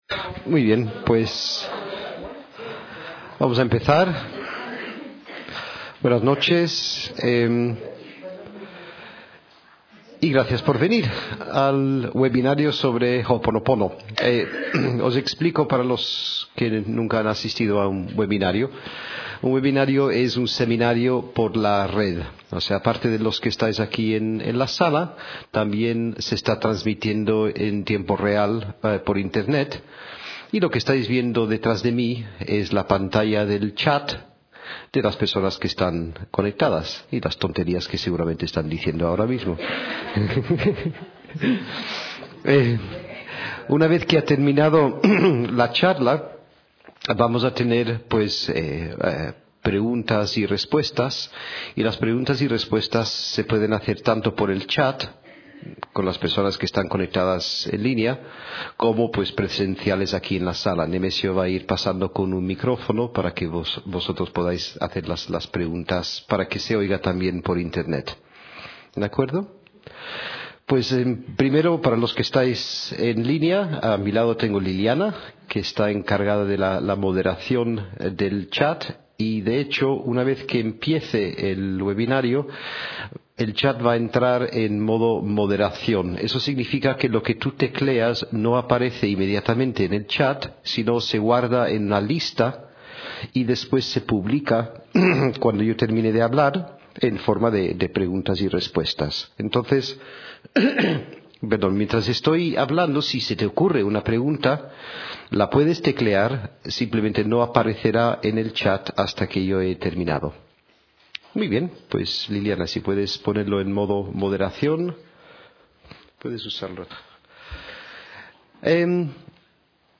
audioconferencia sobre hoponopono